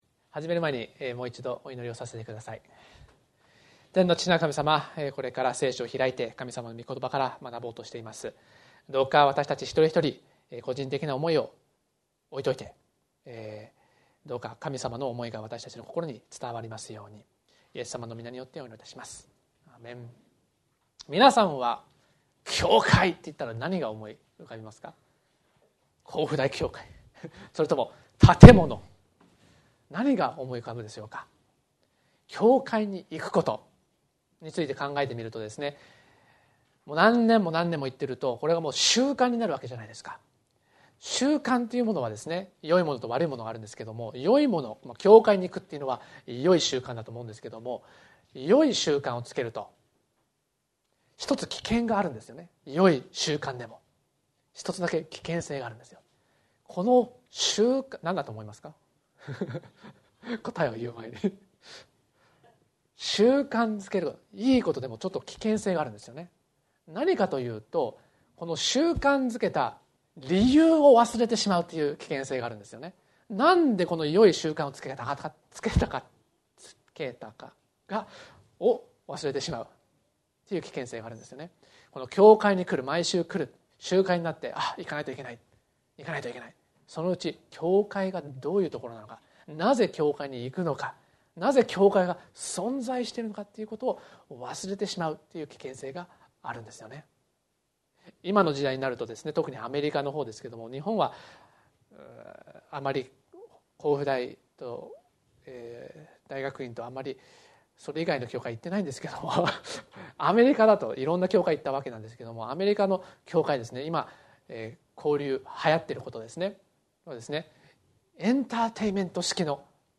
礼拝